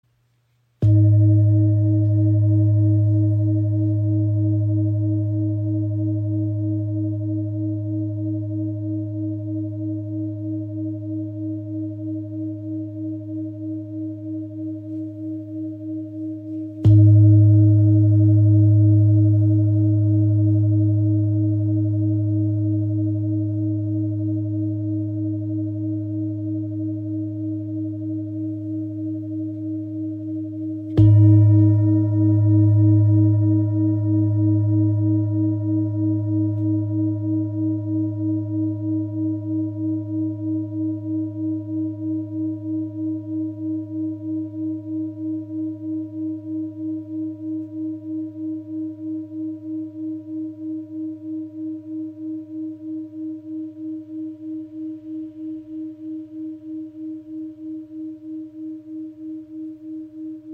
Handgefertigte Klangschale aus Kathmandu
• Icon Inklusive passendem rotem Filzschlägel
• Icon Ton B | Planetenton Siderischer Mond (105,4 Hz) | 2544 g
Klangschale mit weisser Tara | ø 29.5 cm | Ton B | Siderischer Mond